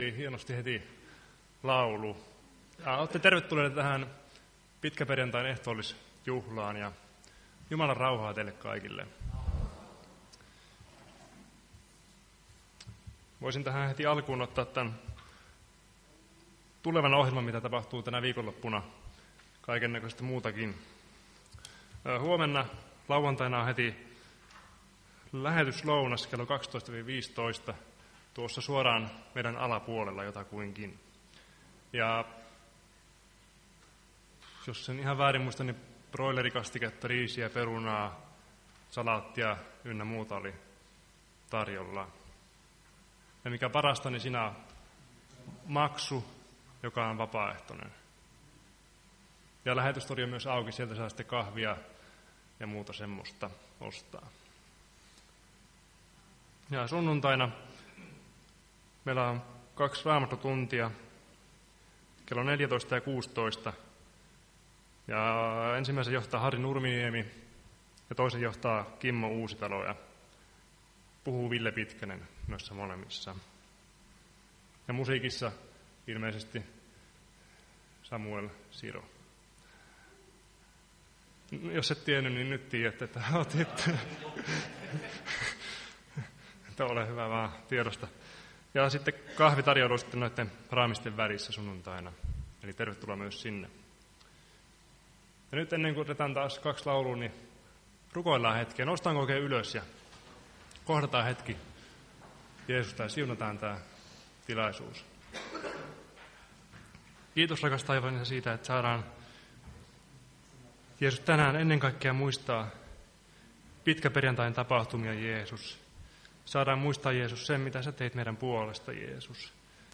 Pitkäperjantain ehtoolliskokous 18.4.2025